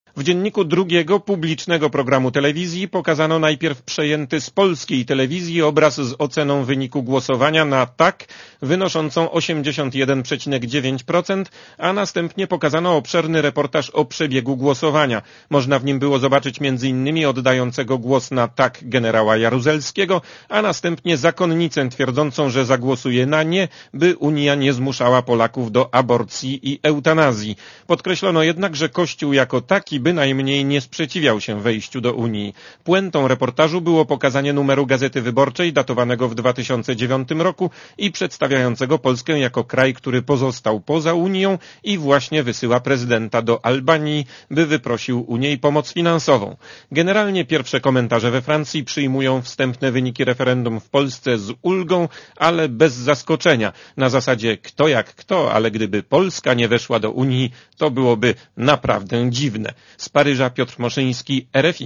Korespondencja z Paryża (244Kb)